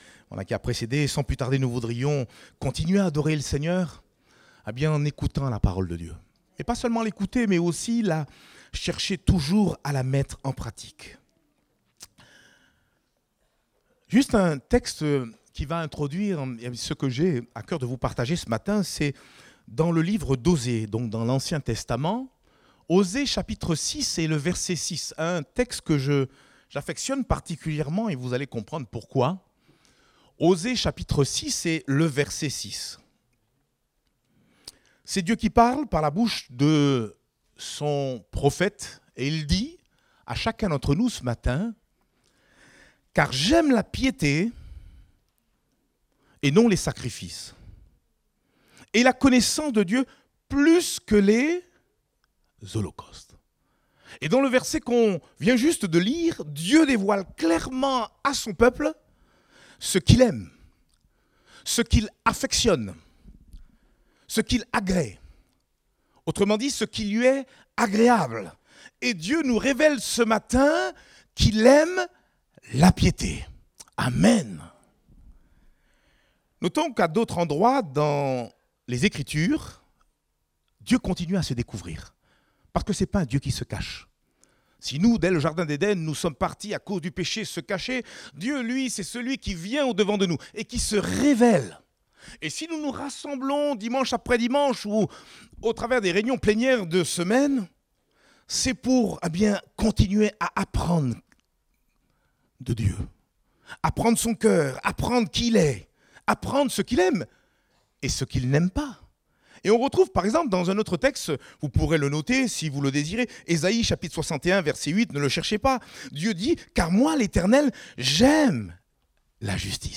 Date : 26 mai 2024 (Culte Dominical)